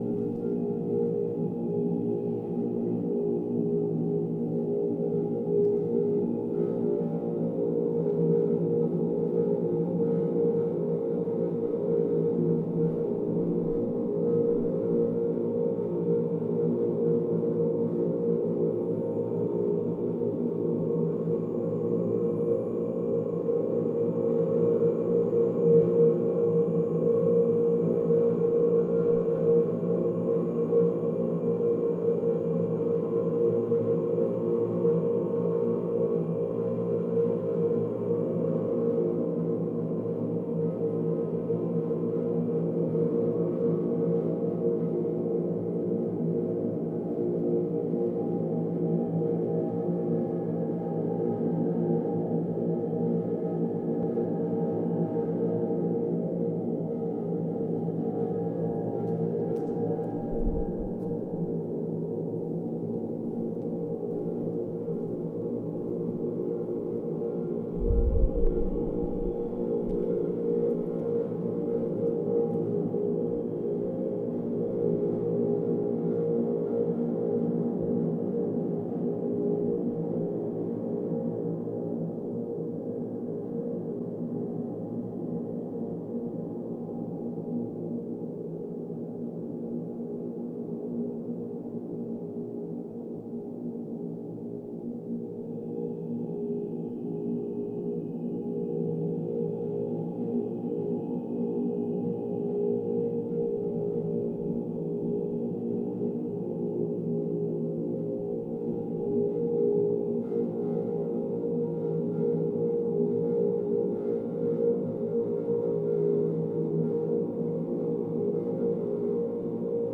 Resonating Sound Body
Big Water Reservoir Berlin 2017
Resonor Big Water Reservoir Berlin 2017 4 sound channels: loudspeakers, metaltubes (3m, 5m, 6m, and 8m long). 30 min composed loop. This site-specific soundinstallation explores the acoustics of the former water reservoir in Berlin Prenzlauer Berg, which has long been the host of the Singuhr Hörgalerie.
Drones and whistling emerge from different directions at different times. The human voice is abstracted through reverberations and resonances of the body of the singer, the tubes, the space.